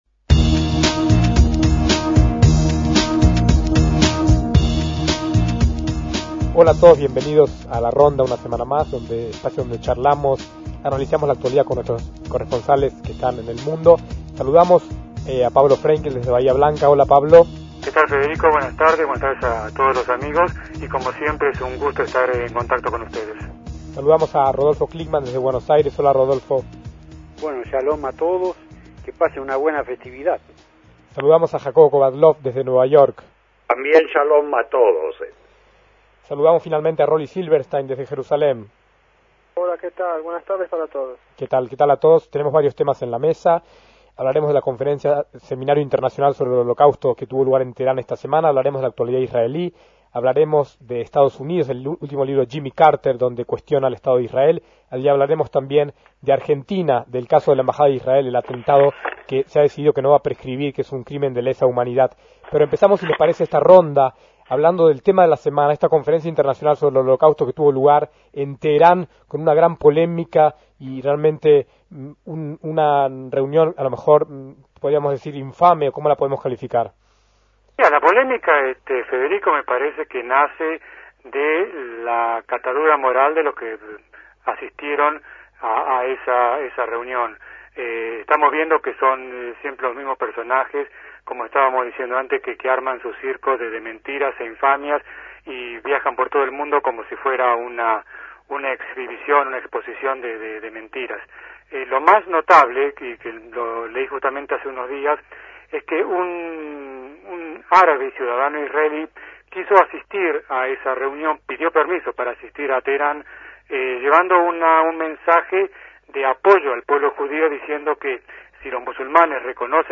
Debate sobre la “Conferencia” acerca del Holocausto en Teherán y la polémica Carter